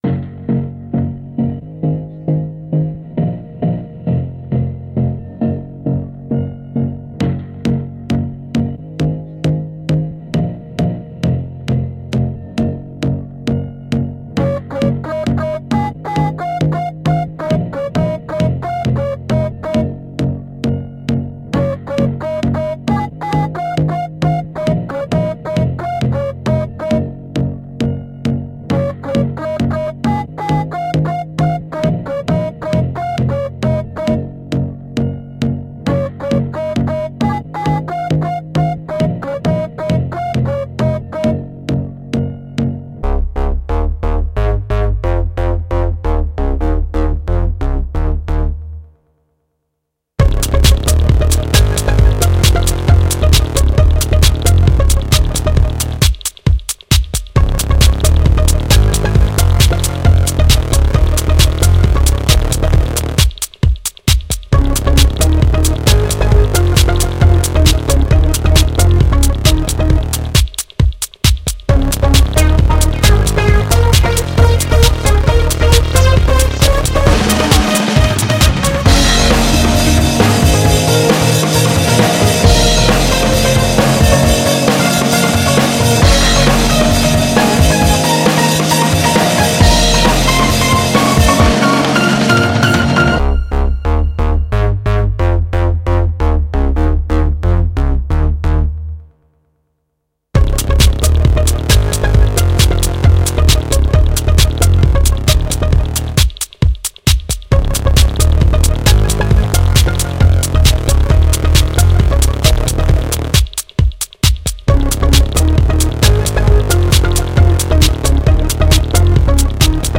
Жанр:Electronic